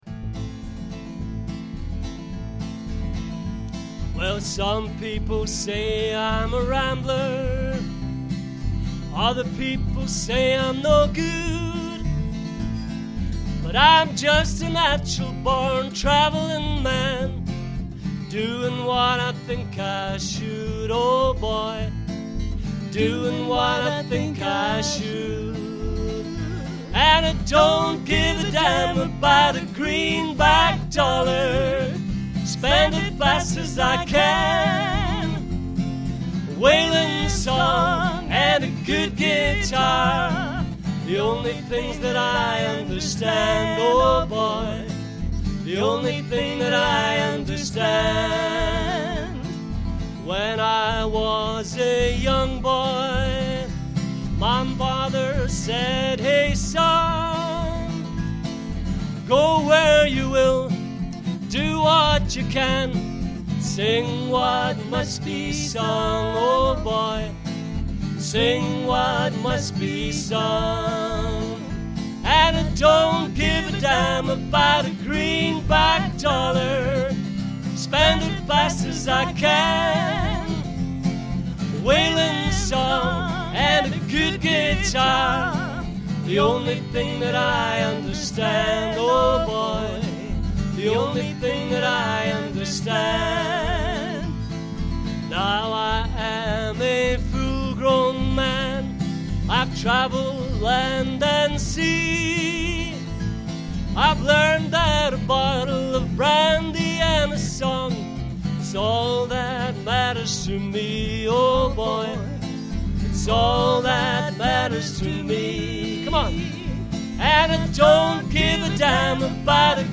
double bass
guitar and harmonica